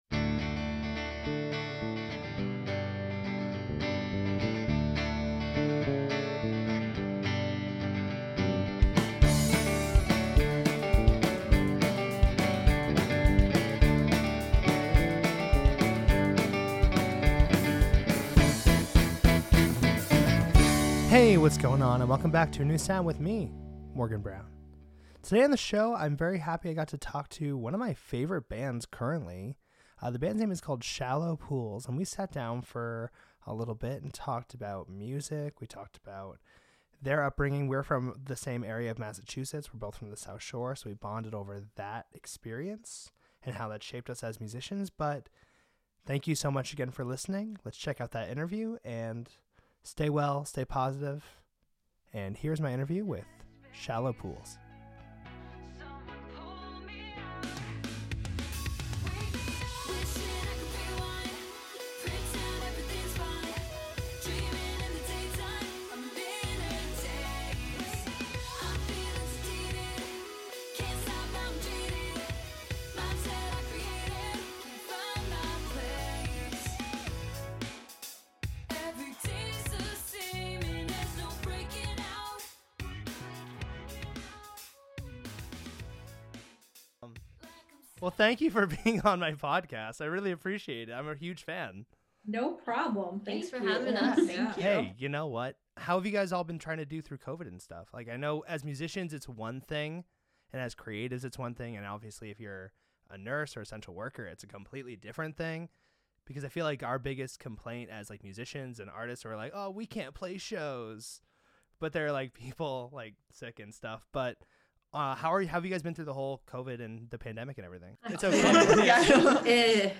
I was lucky to have a quick chat with an amazing band called Shallow Pools! We have a great talk about being from the south shore of MA and how they goy started!